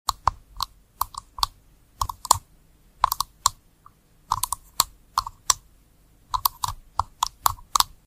Chocolate keyboard ASMR instead of sound effects free download